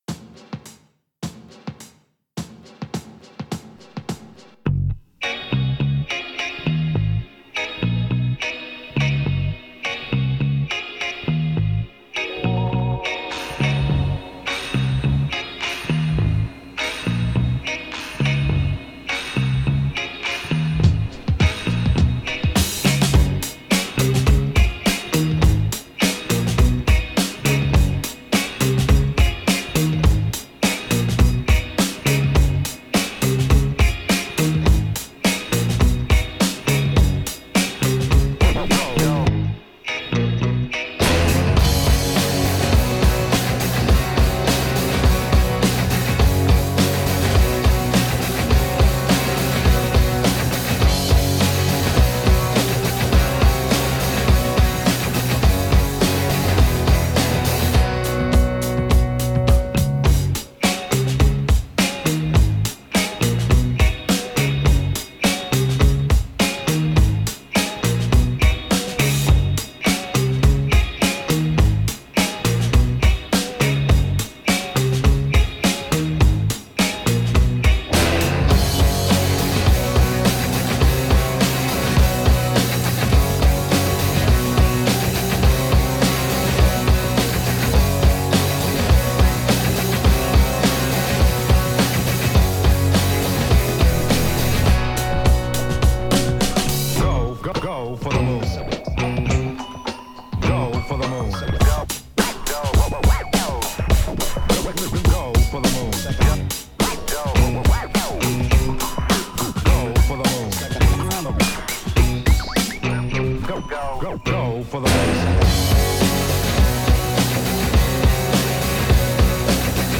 BUT IT DOESN'T HAVE VOCALS